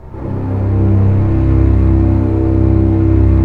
Index of /90_sSampleCDs/Roland - String Master Series/STR_Cbs Arco/STR_Cbs2 Orchest